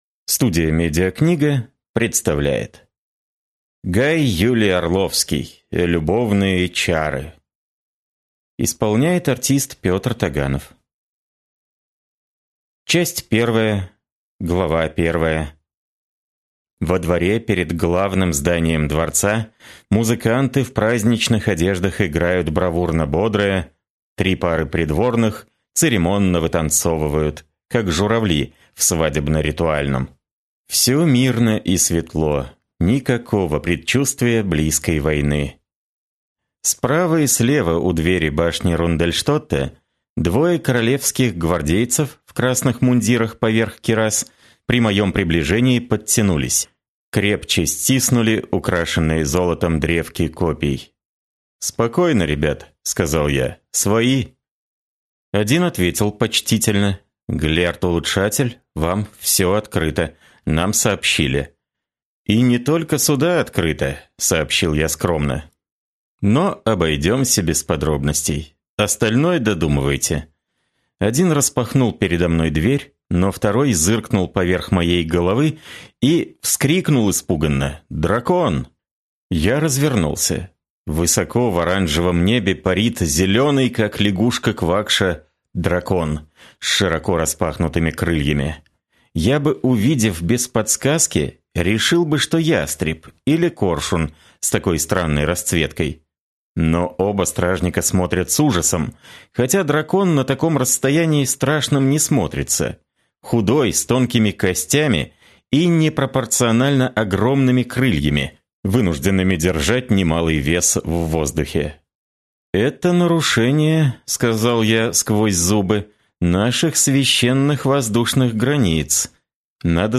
Аудиокнига Любовные чары | Библиотека аудиокниг